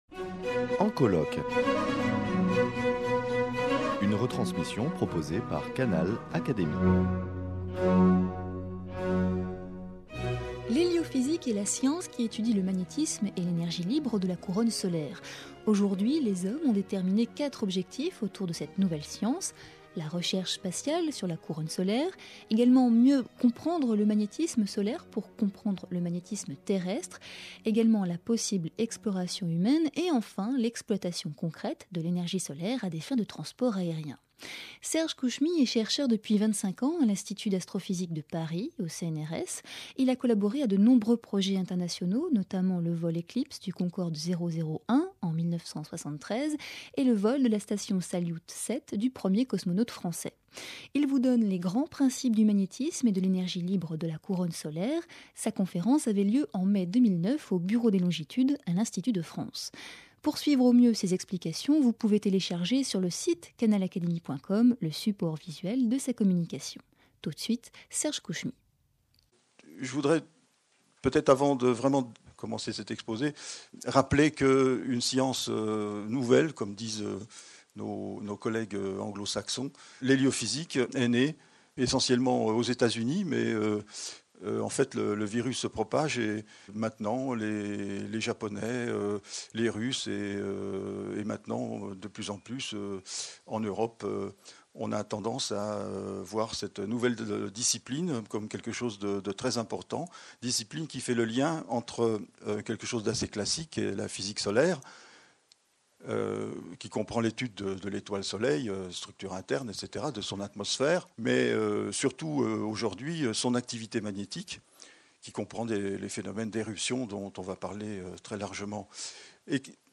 L’heliophysique est la science qui étudie le magnétisme et l’énergie libre de la couronne solaire. Aujourd’hui les hommes ont déterminé quatre objectifs : la recherche spatiale sur la couronne solaire, mieux comprendre le magnétisme solaire pour comprendre le magnétisme terrestre, la possible exploration humaine et l’exploitation concrète de l’énergie solaire à des fins de transports aériens. Explications détaillées au cours de la conférence
donnée au Bureau des longitudes enregistré début mai 2009.